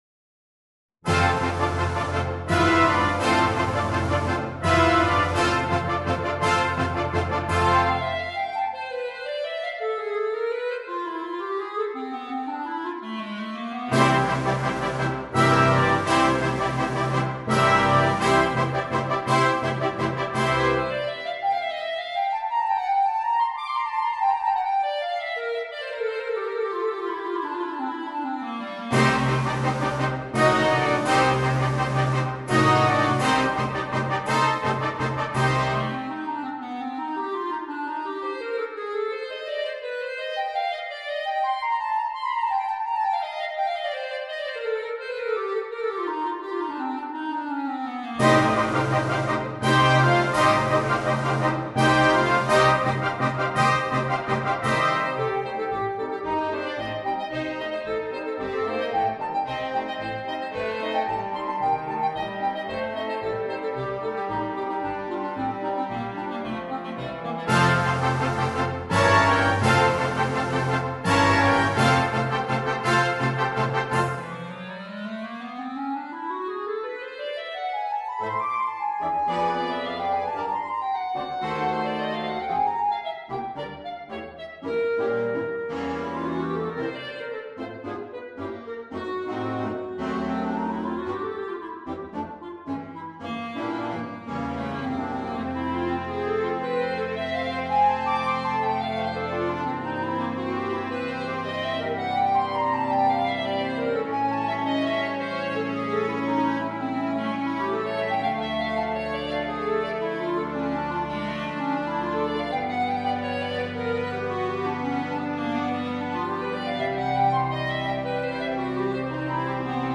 Una composizione impegnatica per clarinetto e banda.